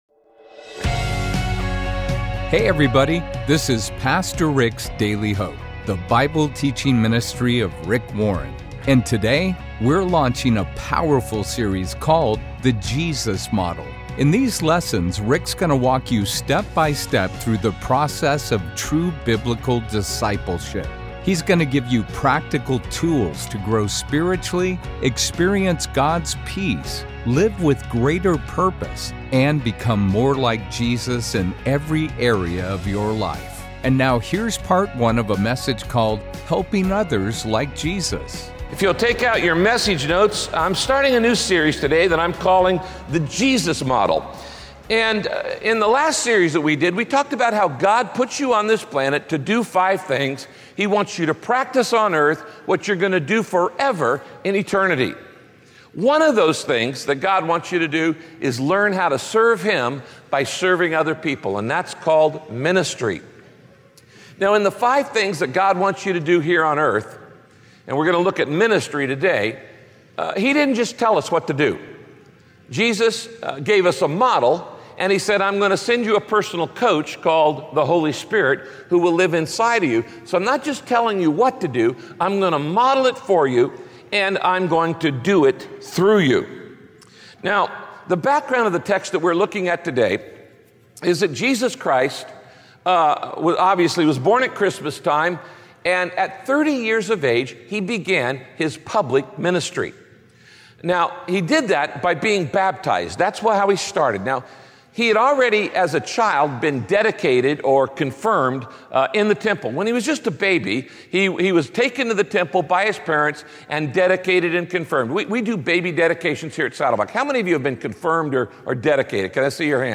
Some lack the money to meet basic needs, others lack a moral compass, and still others are spiritually poor—unaware of the hope and salvation Jesus offers. In this message, Pastor Rick calls us to meet each type of need with compassion and truth.